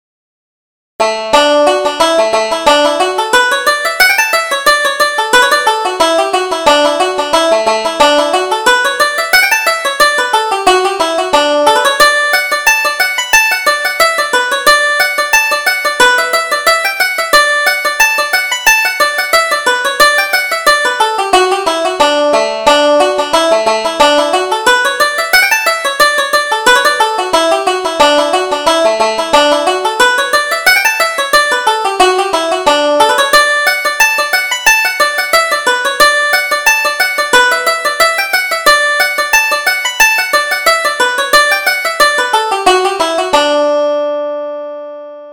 Reel: The Mullingar Races